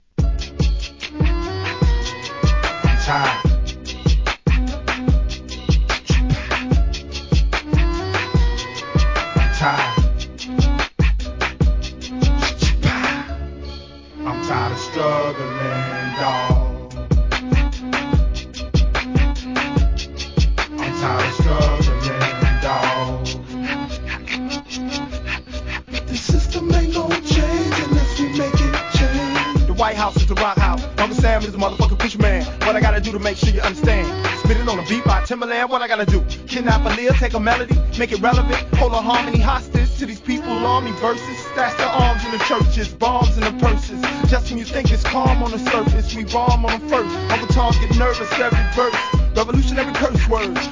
HIP HOP/R&B
バイオリンとピアノが怪しく鳴り響く